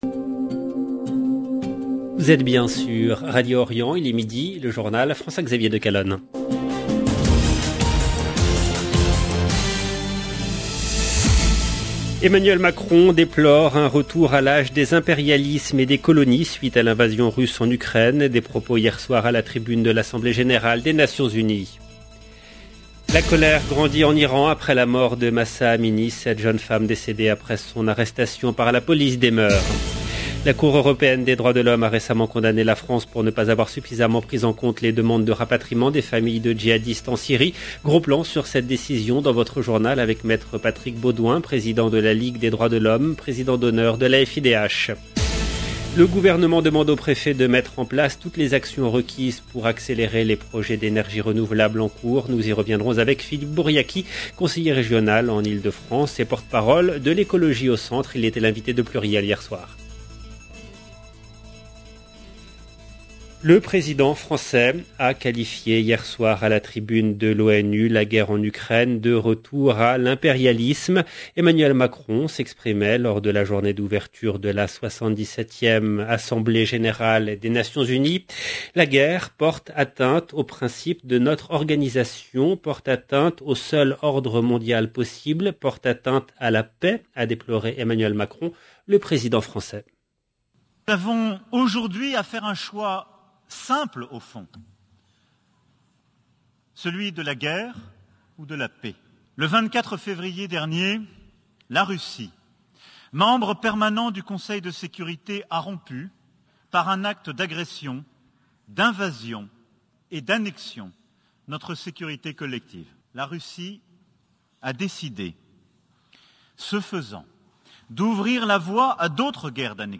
LE JOURNAL DE 12 H EN LANGUE FRANCAISE DU 21/9/2022